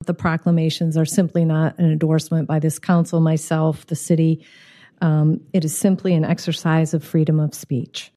AUDIO: Scientology debate breaks out at Portage City Council meeting
Mayor Patricia Randall gave a disclaimer for their proclamations.